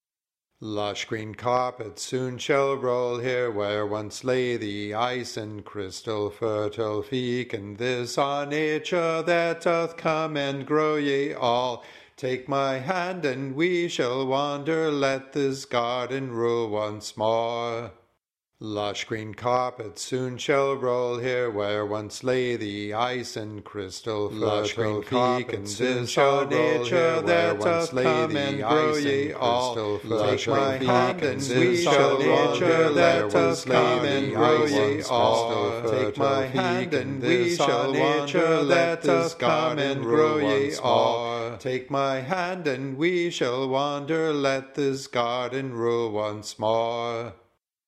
3 vocal parts with myself, which sounds decent thanks to the miracle of computer audio editing.
Spring Round 3 part voice.mp3